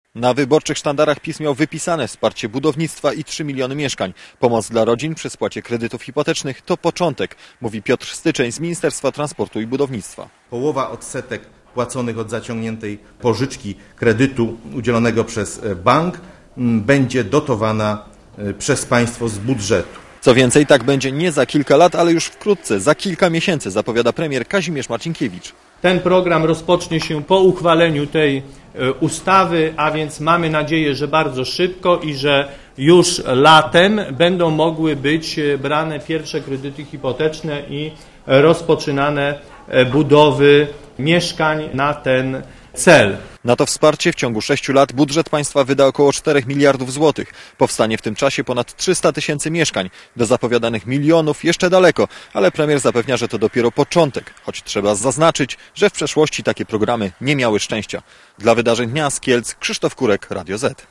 Źródło: Archiwum Relacja reportera Radia ZET Oceń jakość naszego artykułu: Twoja opinia pozwala nam tworzyć lepsze treści.